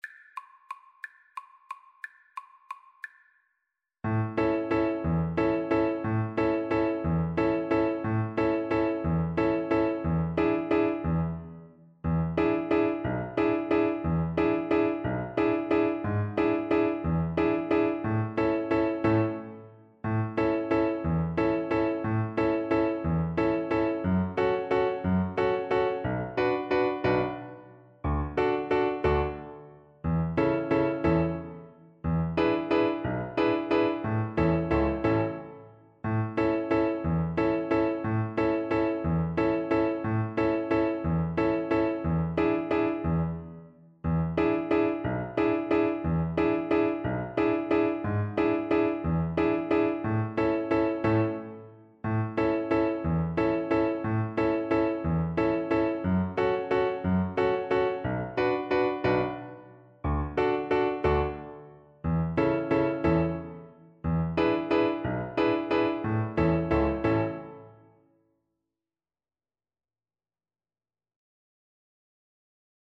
3/4 (View more 3/4 Music)
One in a bar .=c.60
Film (View more Film Violin Music)